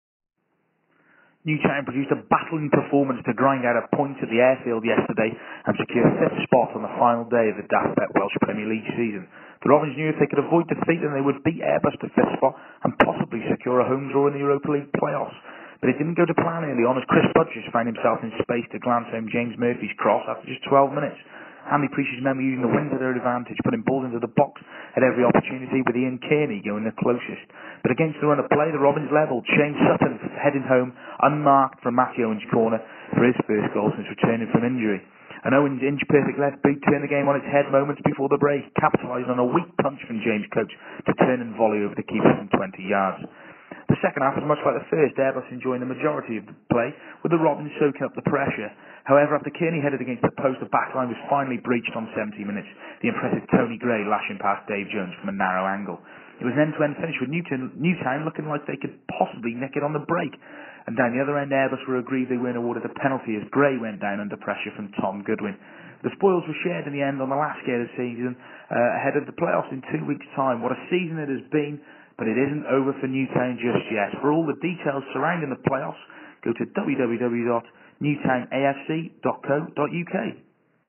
AUDIO REPORT - Airbus 2-2 Robins